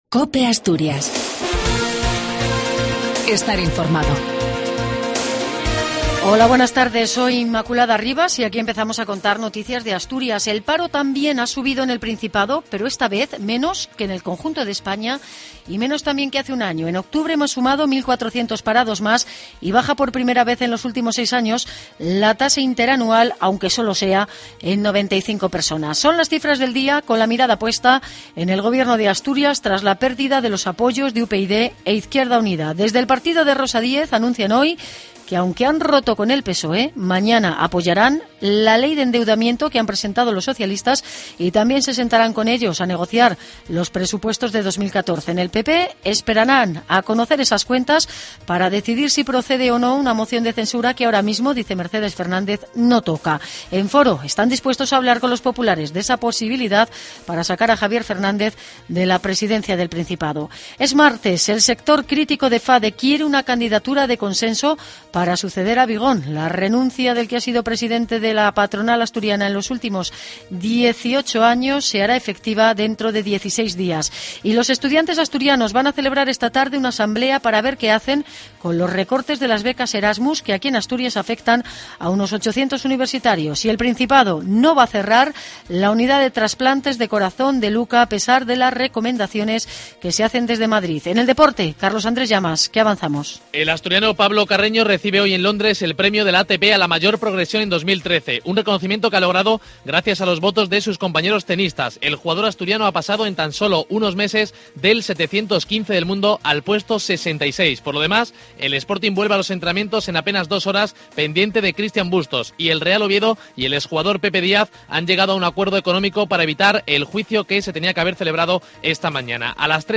AUDIO: LAS NOTICIAS AL MEDIODIA DE ASTURIAS Y OVIEDO.